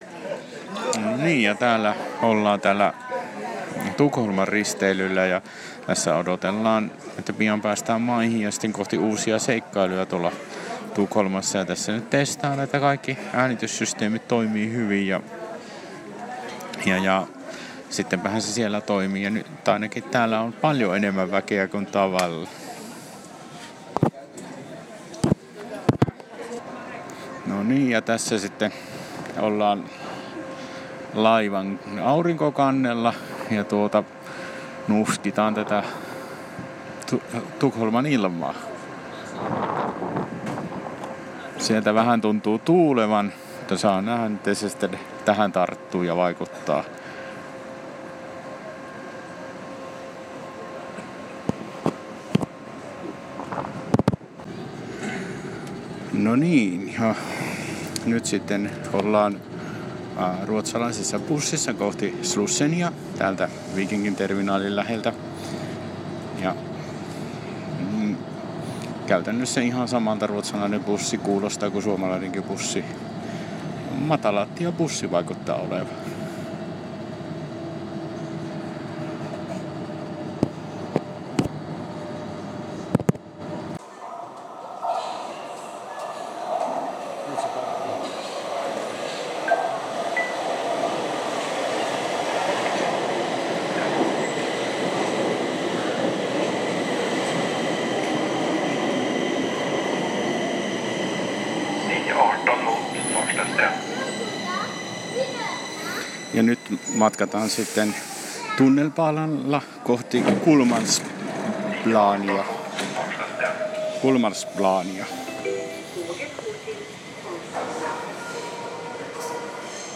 Äänikuvia Tukholmasta Prinsessa-leivosristeilyltä